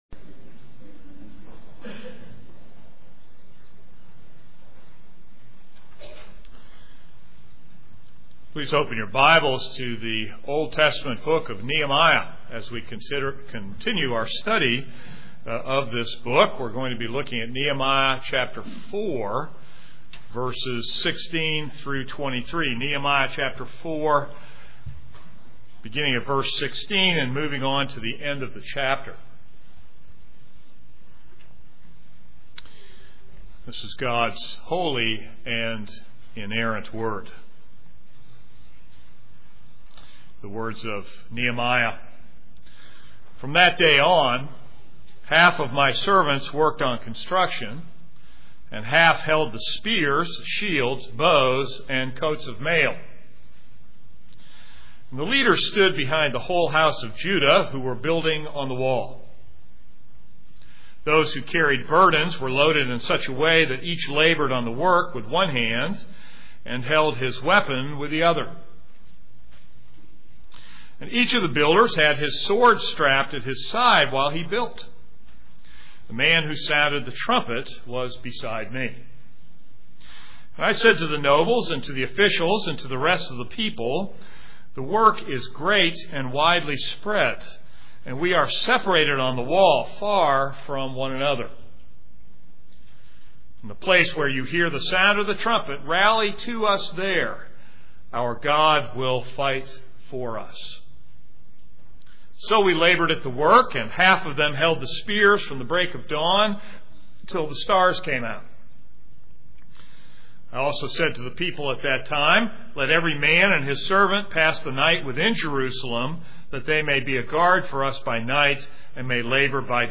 This is a sermon on Nehemiah 4:16-23.